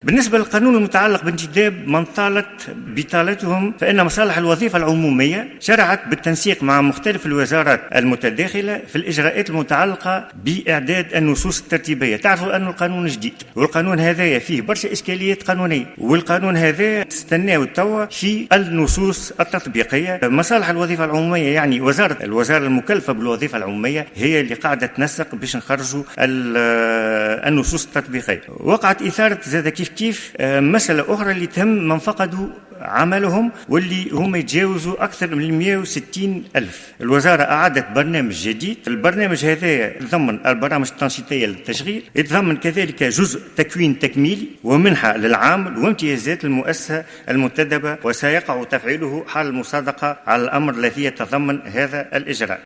قال وزير الشباب والرياضة والادماج المهني، كمال دقيش، أمس الجمعة خلال الجلسة العامة المنعقدة بالبرلمان للاستماع للحكومة حول الوضع الصحي بالبلاد، إن الوزارة أعدت برنامجا خاصا لفائدة أكثر من 160 الف عامل تضرروا من جائحة كورونا وفقدوا مواطن شغلهم.